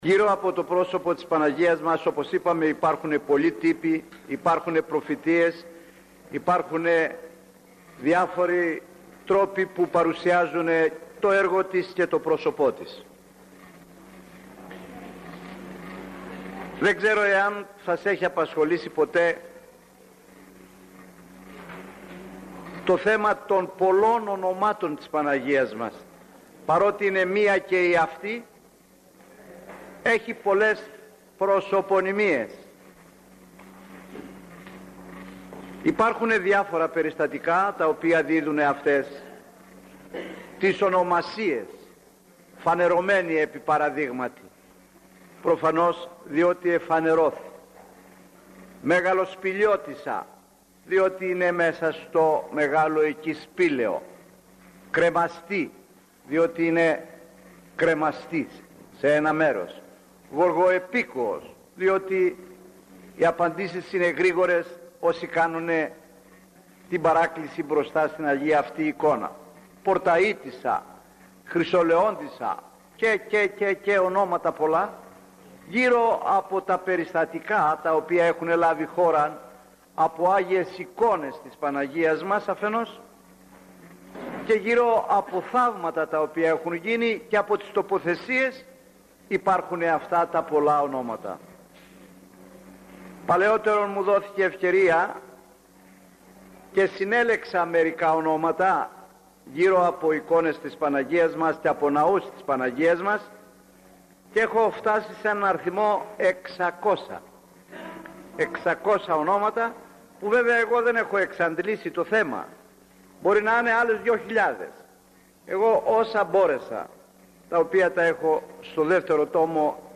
Οι πολλές επωνυμίες και οι εικόνες της Παναγίας μας – ηχογραφημένη ομιλία